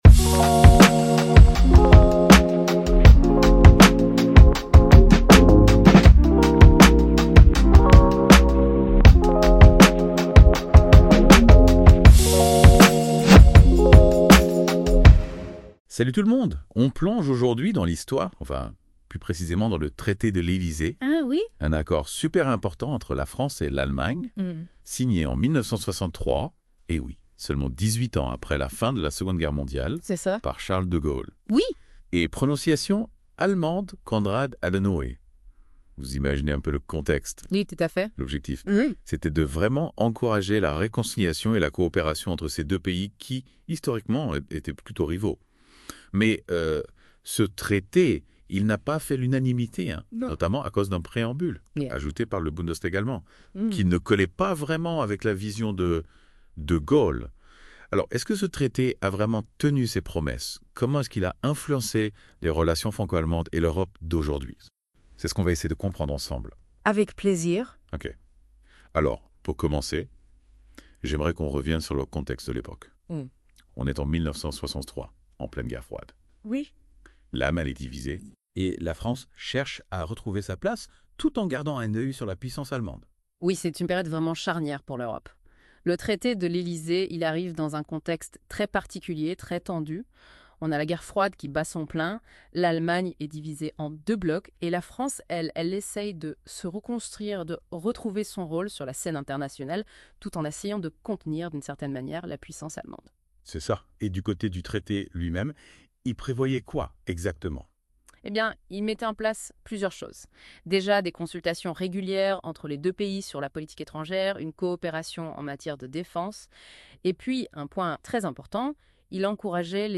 Notebook LM (IA)